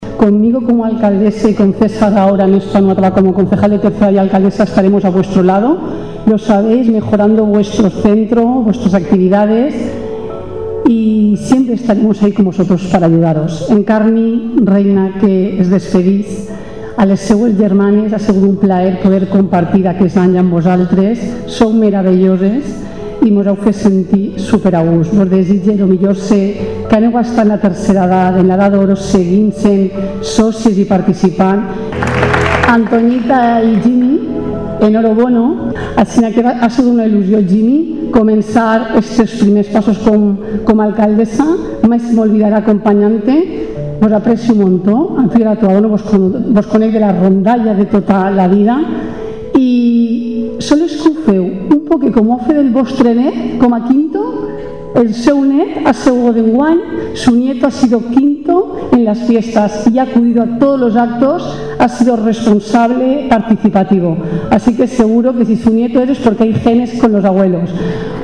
La 33ª edición de la Semana Cultural de la Asociación de Jubilados y Pensionistas “11 de septiembre” de Pinoso arrancó este jueves en el auditorio municipal.
La alcaldesa en funciones, Silvia Verdú, que mañana viernes tomará posesión oficialmente del cargo, subrayó en su intervención el valor de los mayores en la vida del municipio: